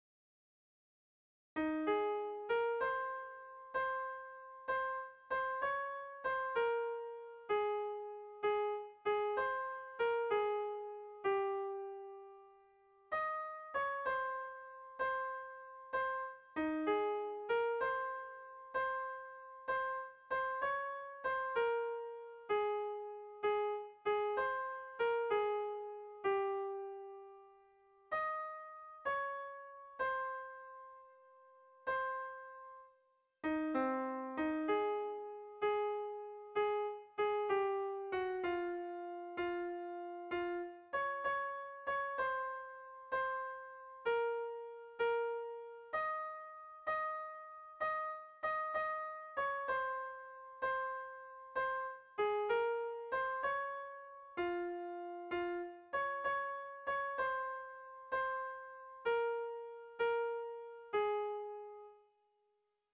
Kontakizunezkoa
Zortziko handia (hg) / Lau puntuko handia (ip)
AABD